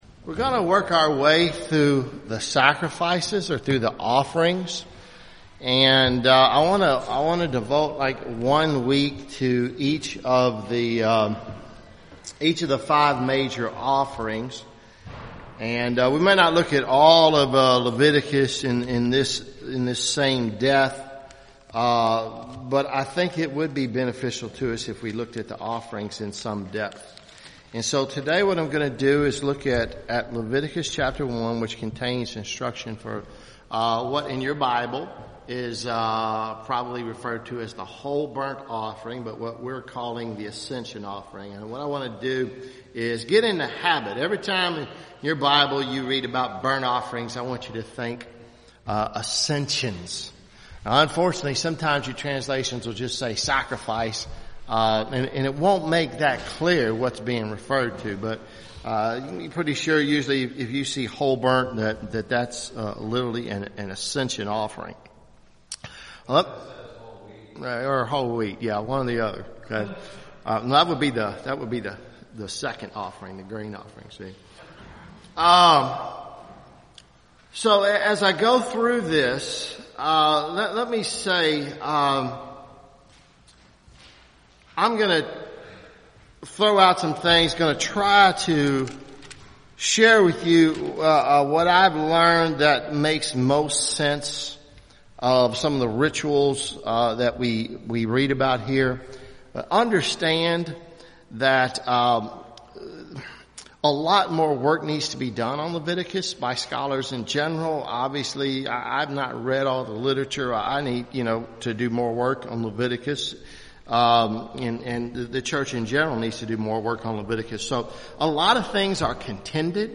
Sunday school series on Leviticus